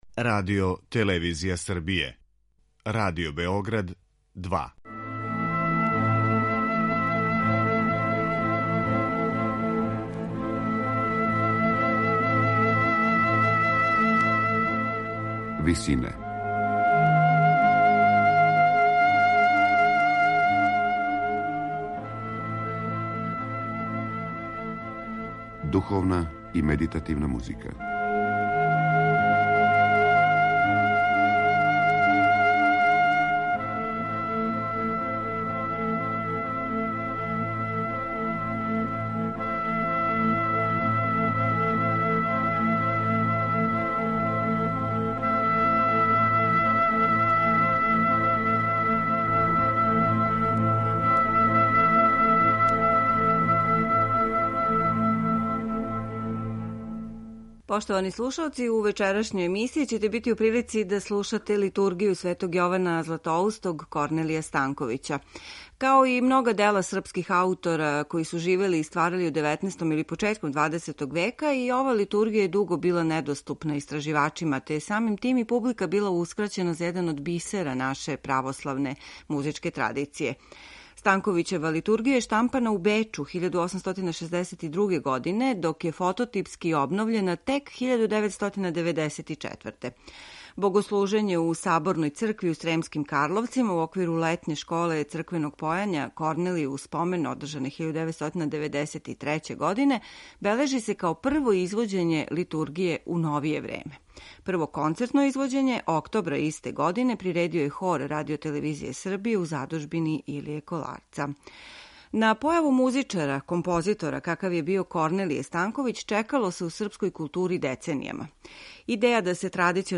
Литругија Светог Јована Златоустог Корнелија Станковића, заснована на напевима традиционалног карловачког појања, дело је једноставних хармонија и хорског слога, које има историјски значај у развоју наше музике.
мешовити хор